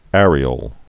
(ârē-ōl)